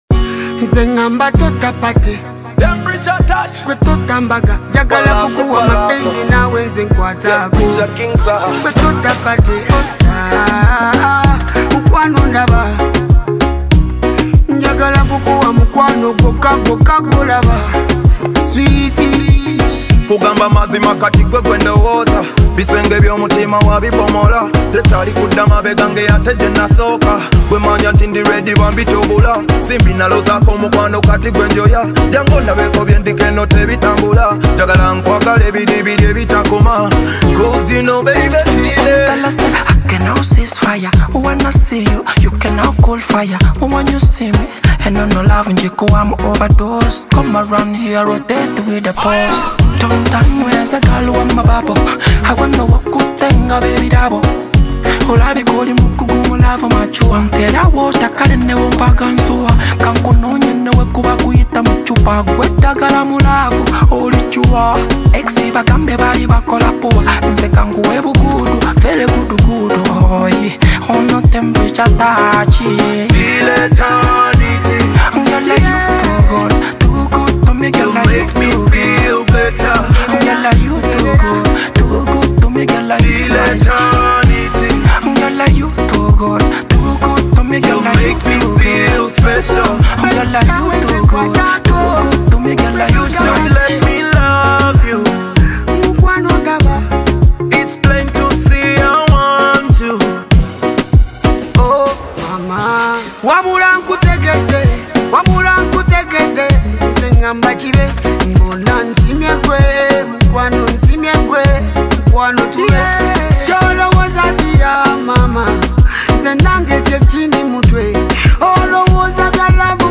Download free MP3 Ugandan music
DANCE VERSION